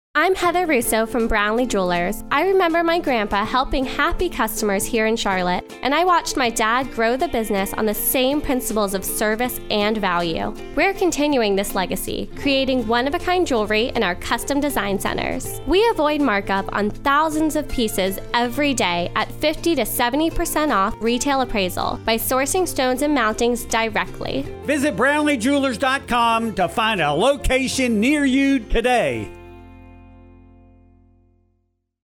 BLJ-Radio-30-Growing-Up-Brownlee.mp3